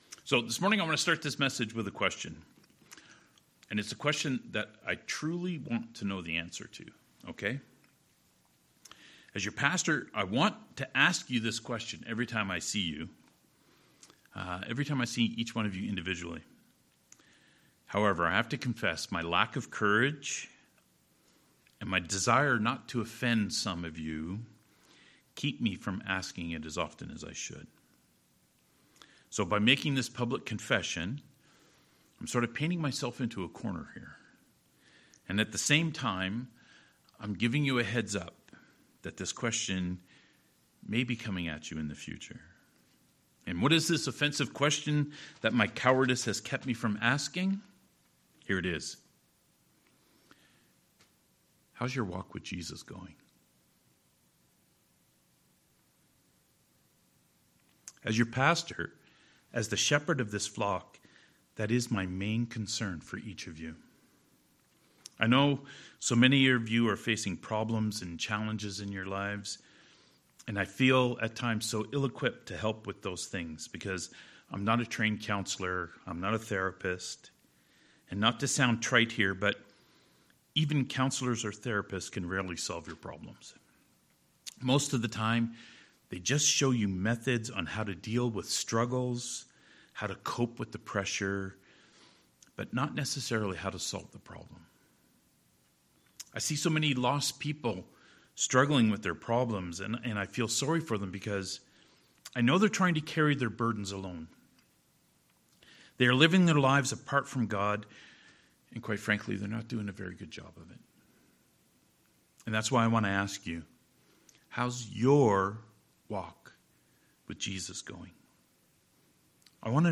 John "So That You May Believe" Passage: John 7: 45-52, John 8: 12-20 Service Type: Sermons